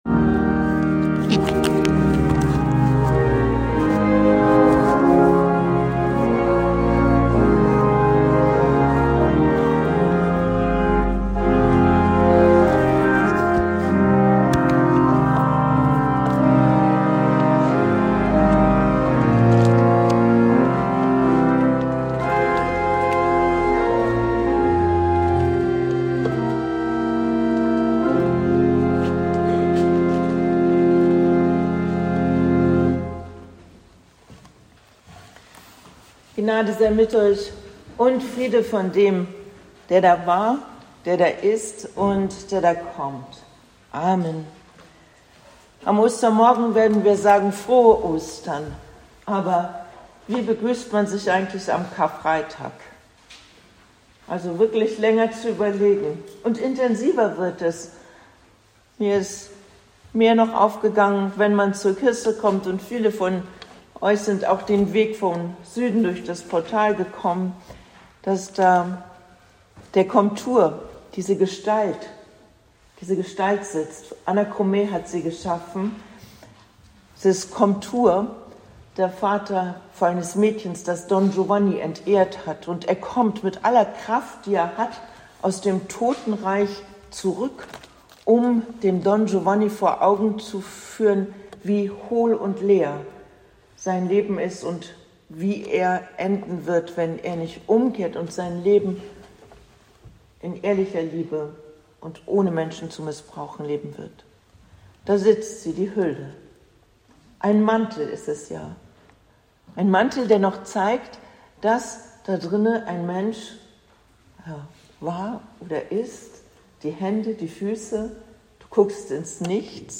Predigt am Karfreitag 2025